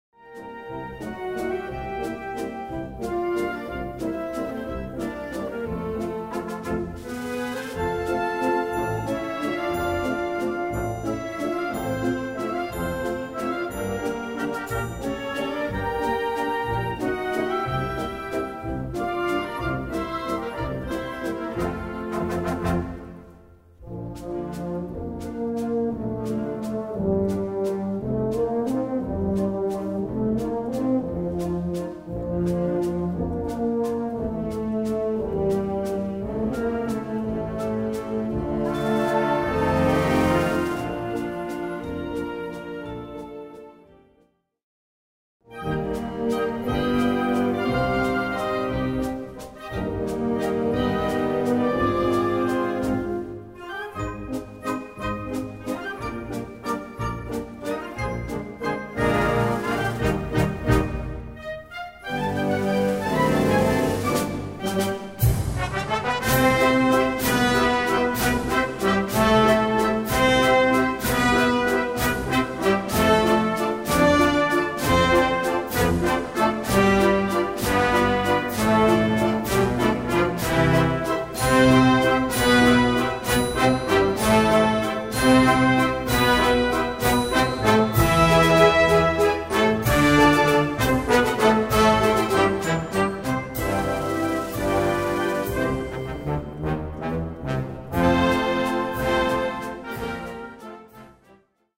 Gattung: Walzerpotpourri
Besetzung: Blasorchester